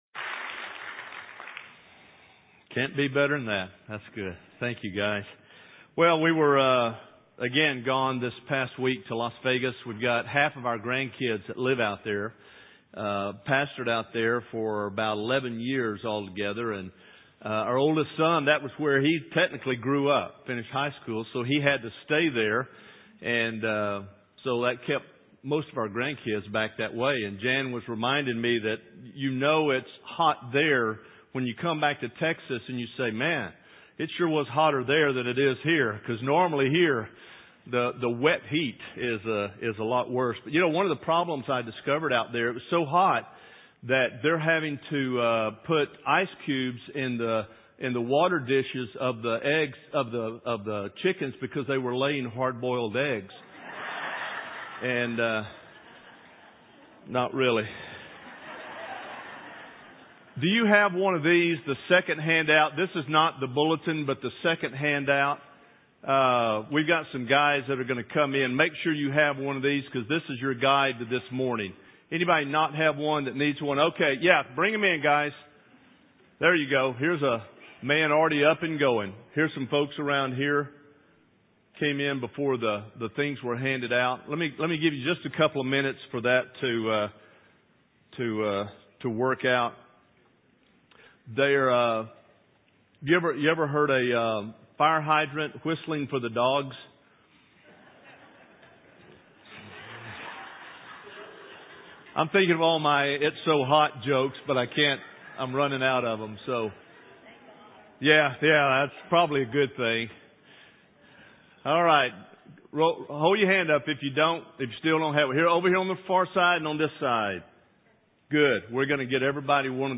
North Shore Church Messages